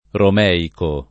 romeico [ rom $ iko ] → romaico